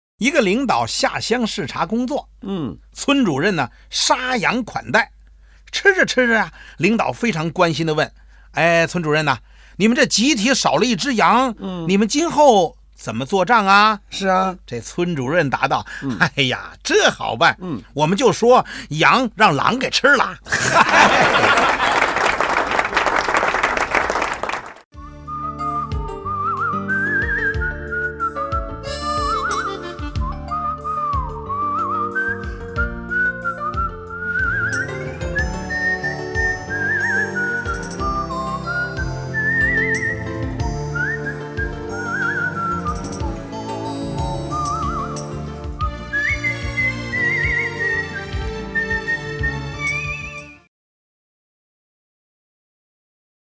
长途旅行,驾车或者坐车,觉得困乏,那就来听听这笑话吧...专业人员配音，轻松的音乐，笑哈之际把困乏丢之脑后！